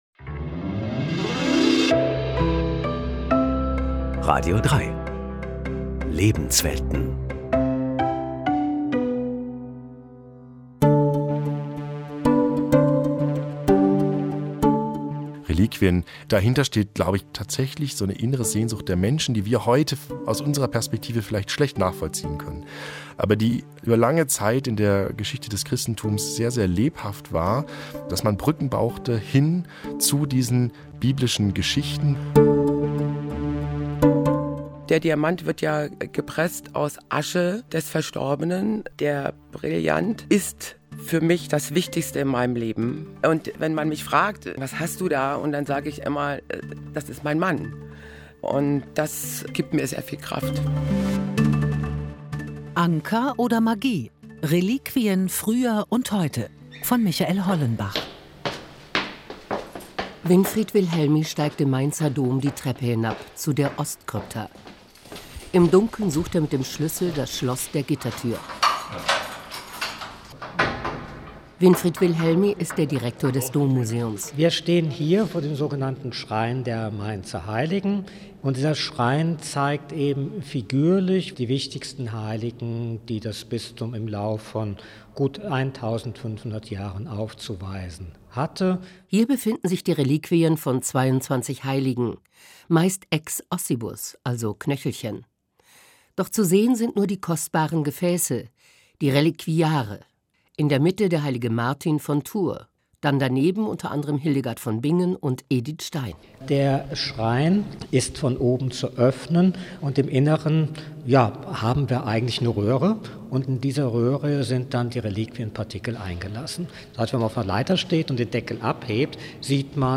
Sinnliche Reportagen, nüchterne Analysen, Porträts von lebenden und historischen Persönlichkeiten, philosophische Betrachtungen existenzieller Themen, kritische Berichte zu aktuellen Fragen, sowie Lebensberatung und Aufklärung.